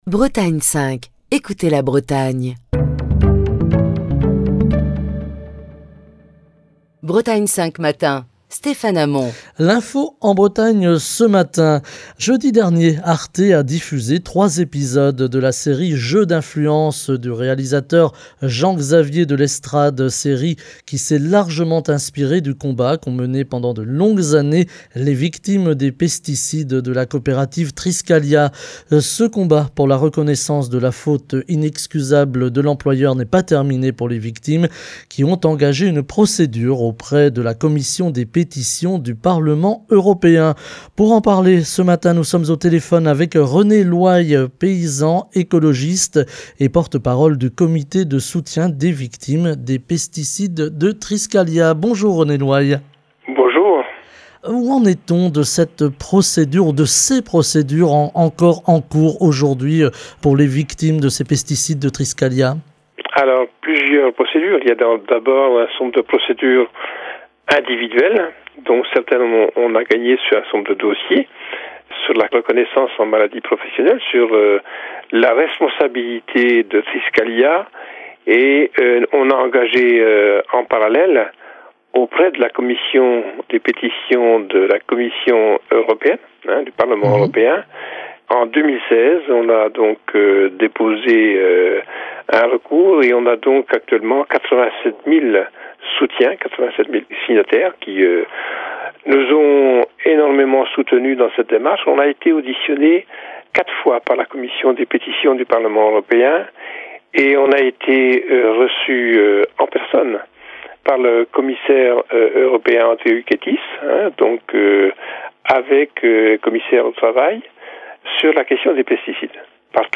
Émission du 11 janvier 2023.